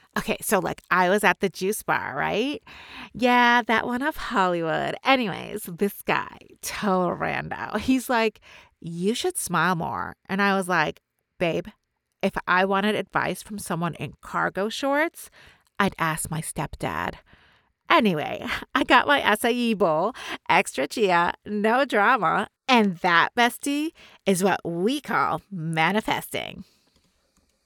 Character Samples
Valley-Girl.mp3